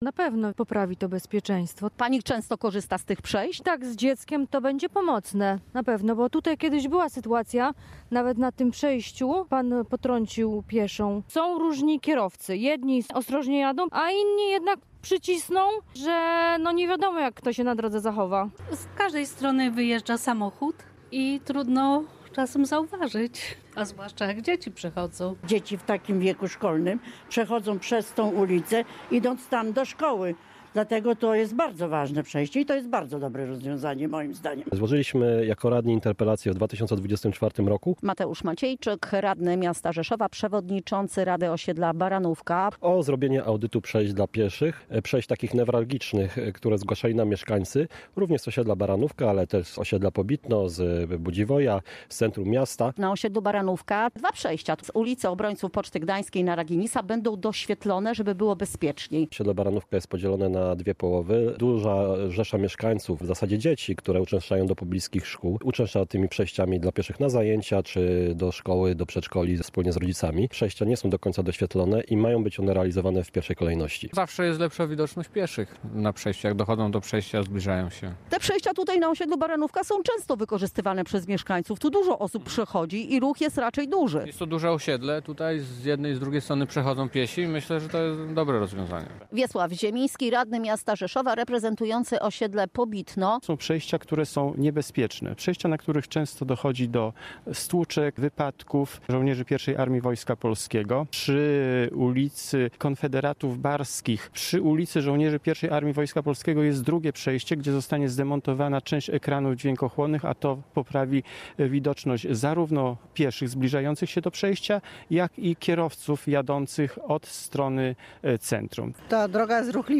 Relację